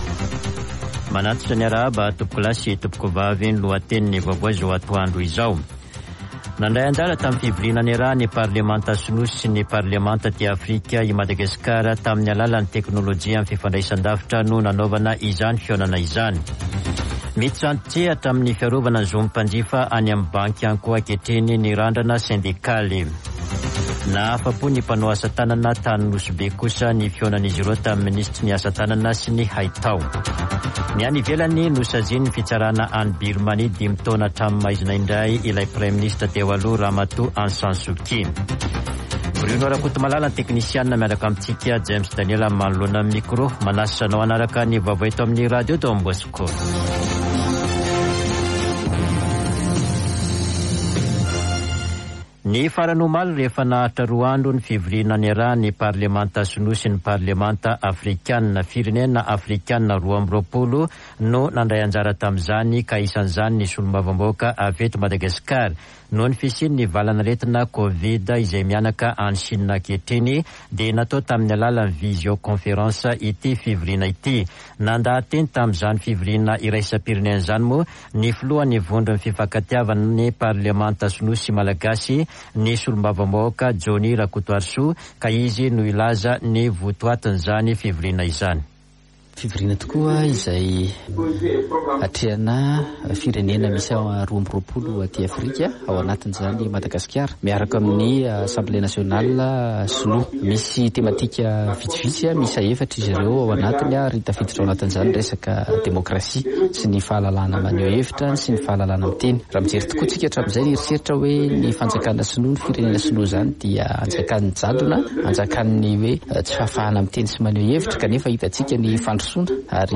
[Vaovao antoandro] Alakamisy 28 avrily 2022